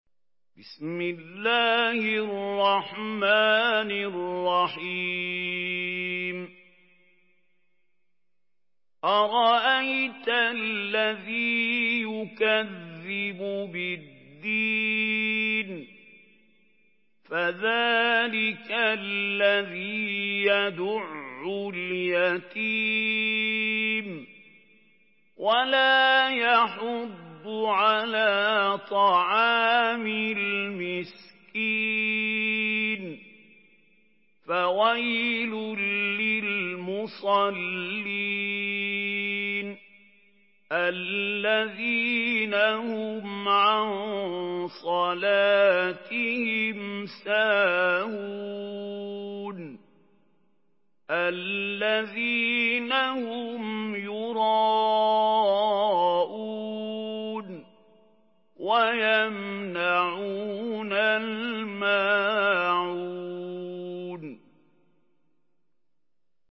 Surah আল-মা‘ঊন MP3 by Mahmoud Khalil Al-Hussary in Hafs An Asim narration.
Murattal